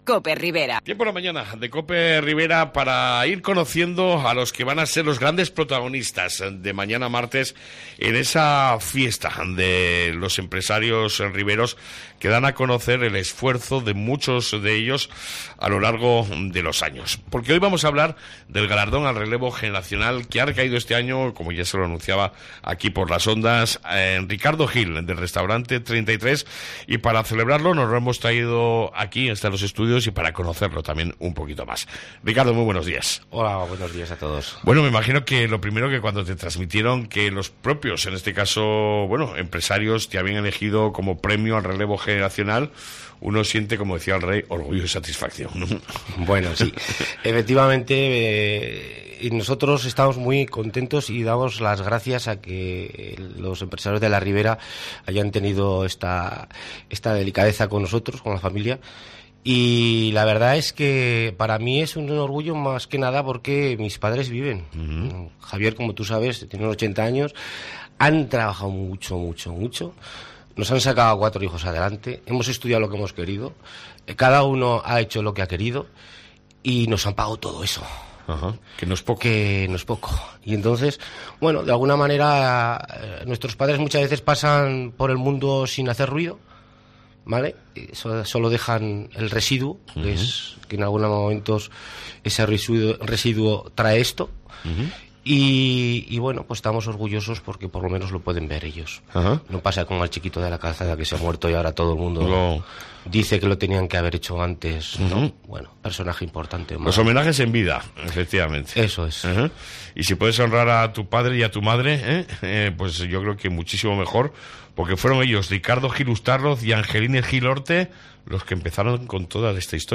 LA MAÑANA DE COPE RIBERA Entrevista